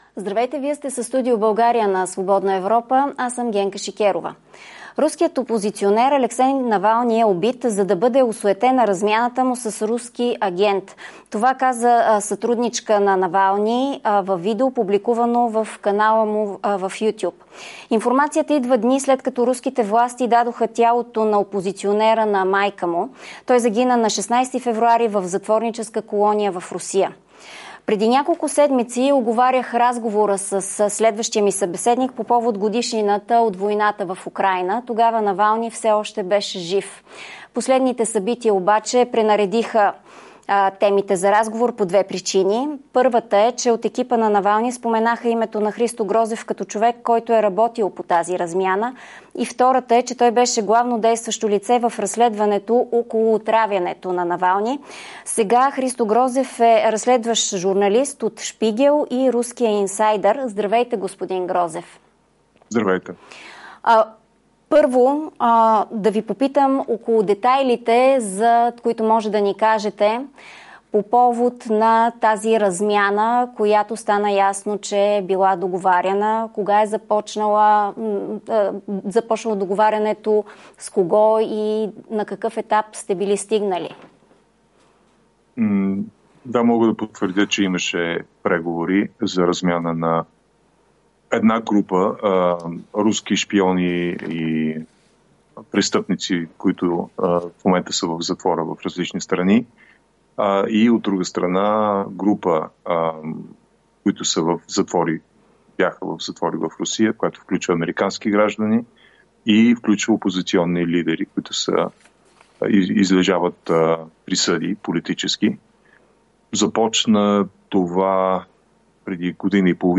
Как се стигна до смъртта на Навални? Разговор с Христо Грозев
Генка Шикерова разговаря с Грозев още и за войната на Русия срещу Украйна и руското влияние в България.